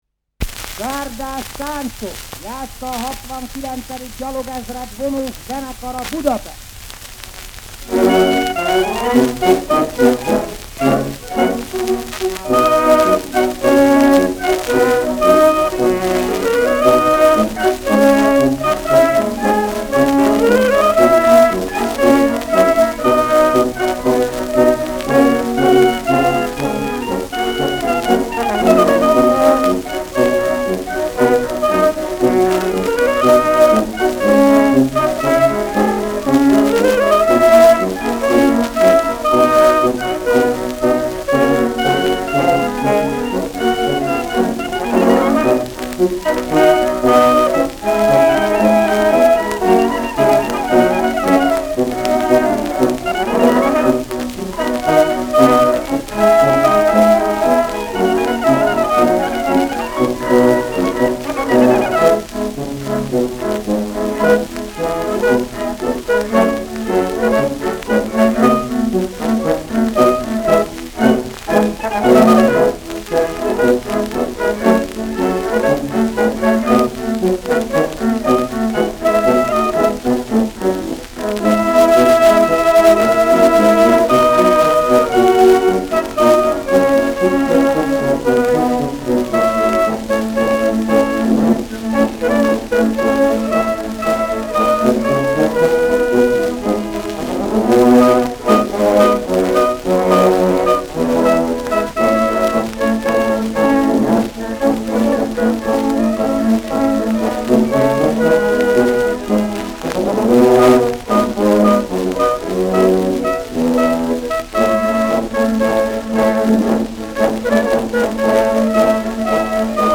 Schellackplatte
präsentes Rauschen : präsentes Knistern : leichtes Leiern : abgespielt
Streichorchester des 69. k.u.k. Infanterie-Regiments, Budapest (Interpretation)